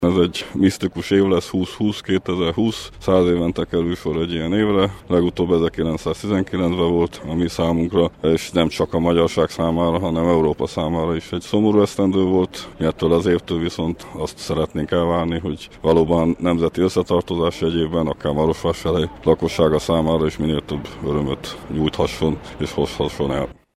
Az új év első napján a marosvásárhelyi Petőfi szobornál megemlékezést tartottak, a költő születésének 197. évfordulóján, az Erdélyi Magyar Közművelődési Egyesület (EMKE) szervezésében.